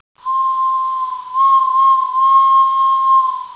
whistle.wav